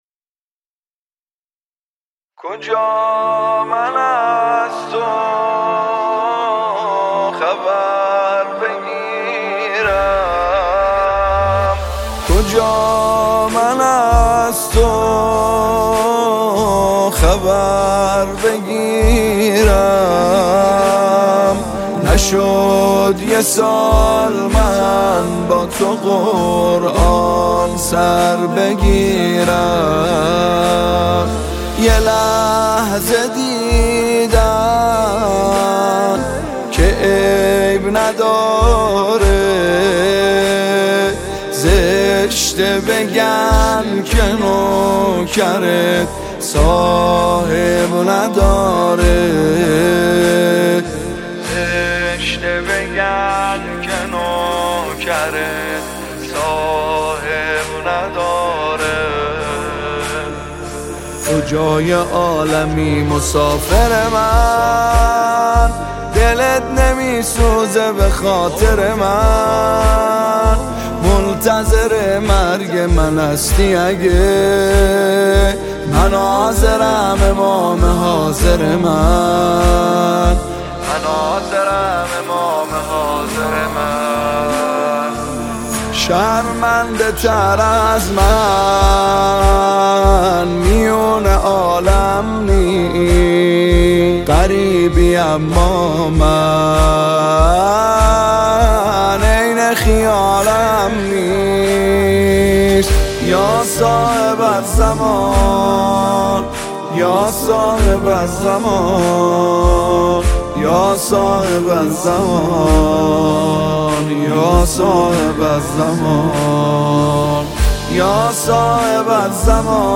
نماهنگ و مناجات مهدوی
با نوای دلنشین